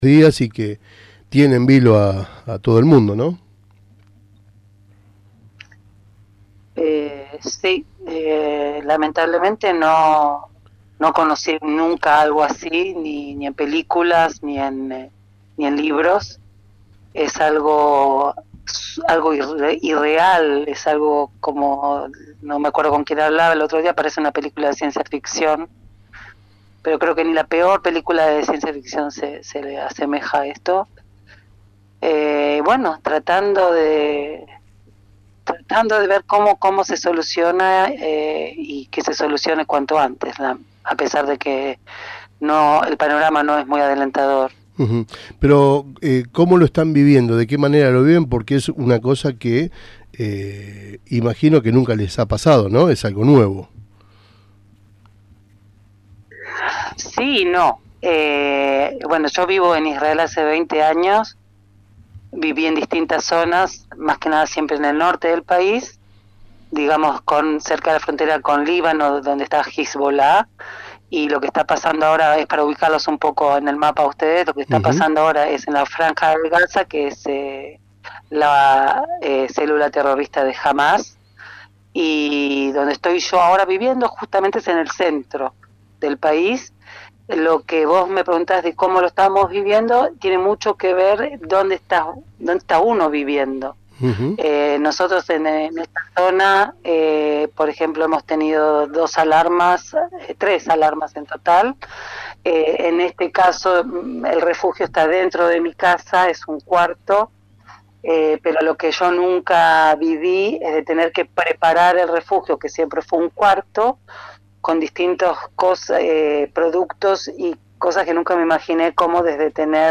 Al momento en que se estaba realizando la entrevista, se escucha que comienza a sonar la alarma en su ciudad, por lo que abandona abruptamente la entrevista que estábamos realizando.